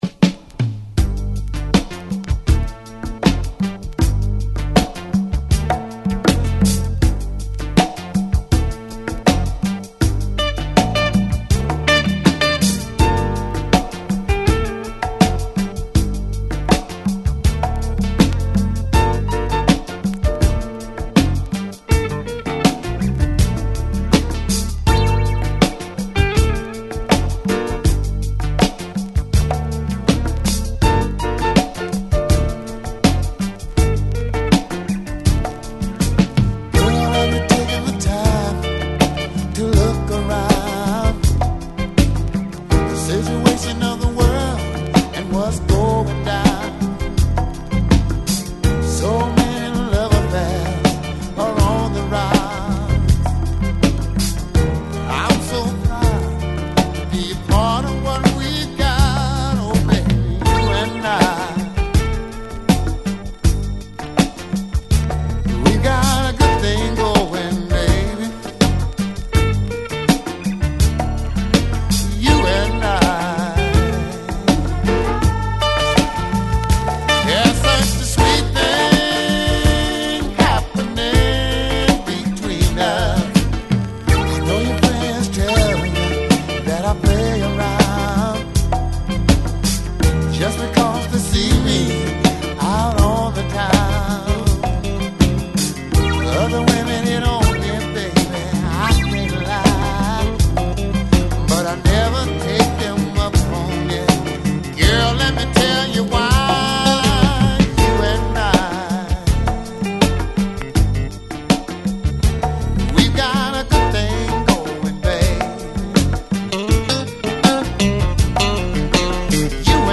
ホーム DISCO 80's 12' G